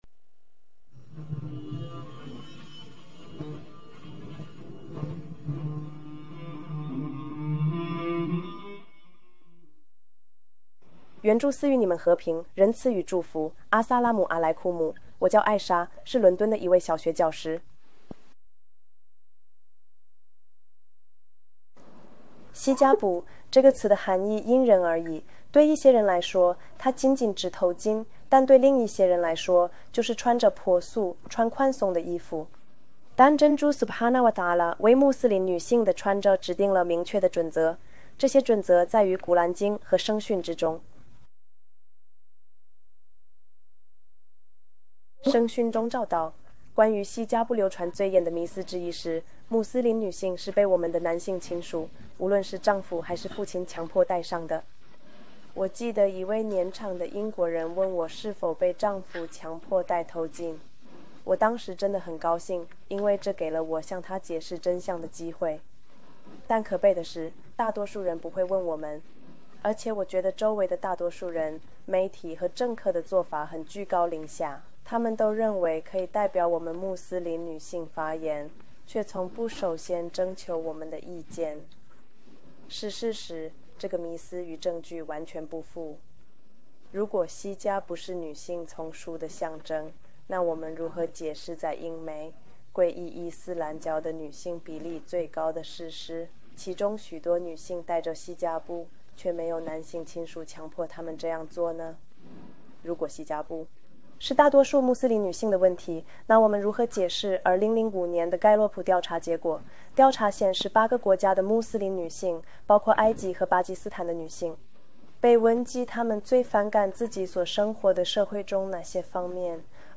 与宣传者的主观观点不同，穆斯林女性强烈表达了佩戴头巾的意愿。